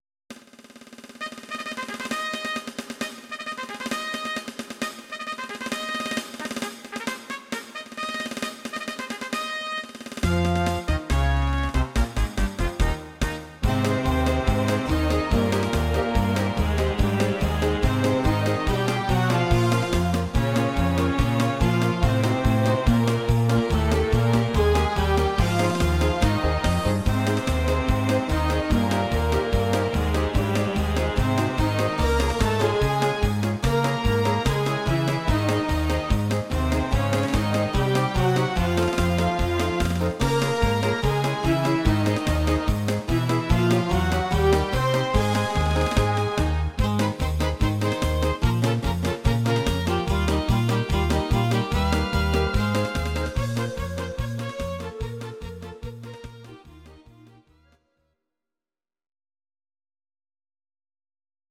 Audio Recordings based on Midi-files
Ital/French/Span